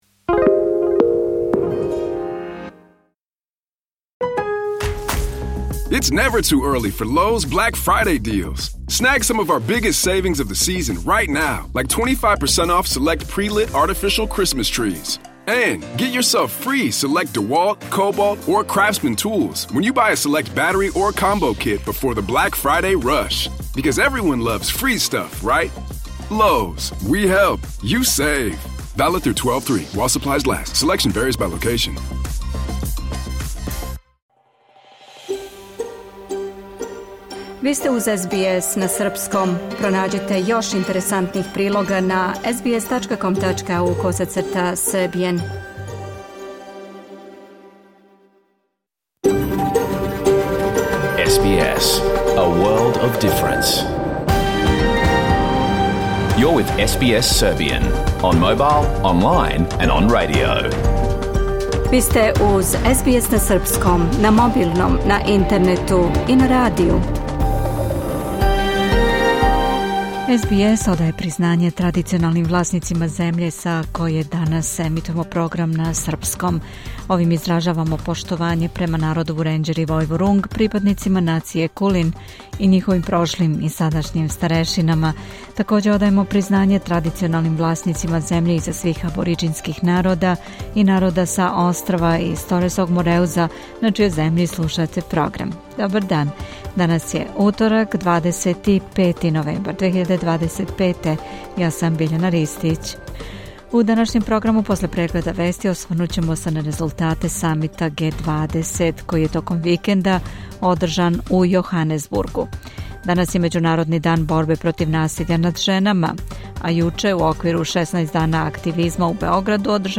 Програм емитован уживо 25. новембра 2025. године